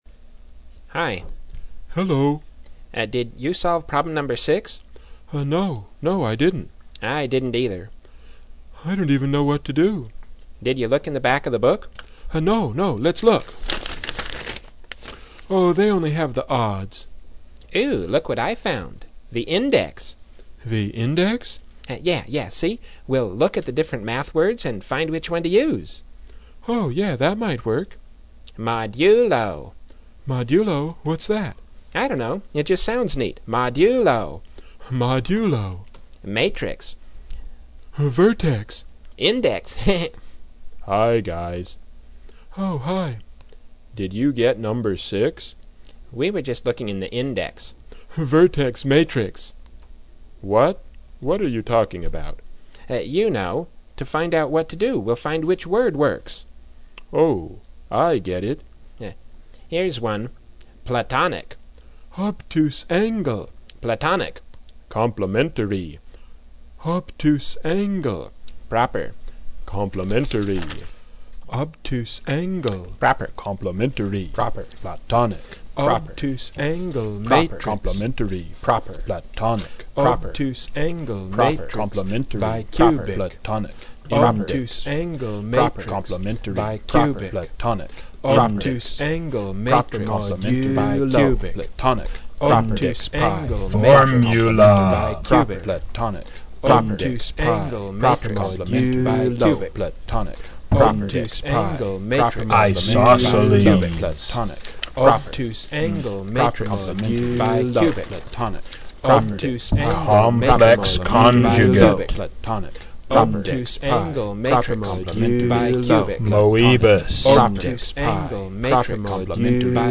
funny song